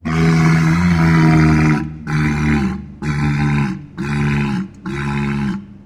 Рык зрелого моржа